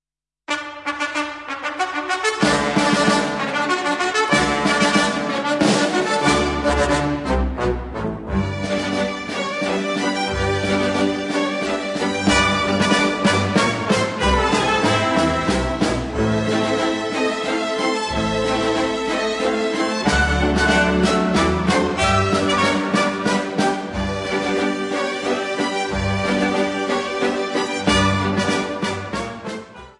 (Nagrania archiwalne z lat 1962-1974)